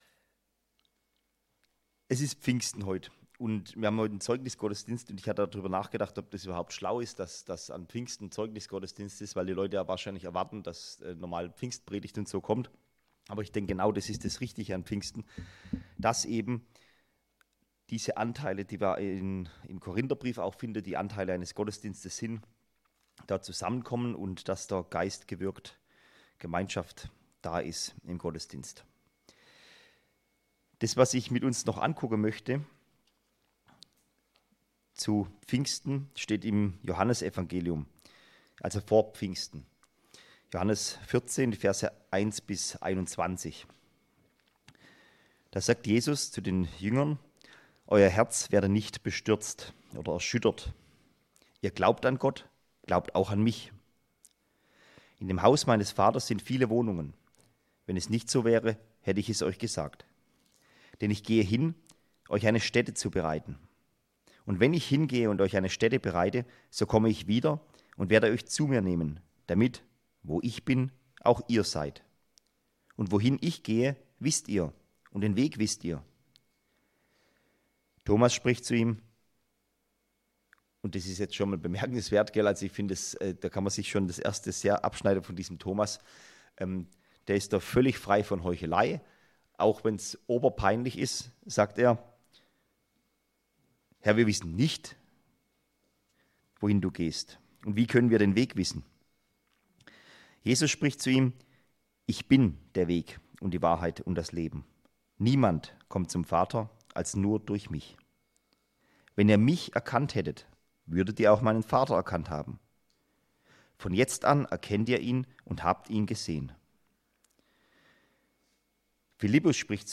Psalm 51 Anlass: Pfingsten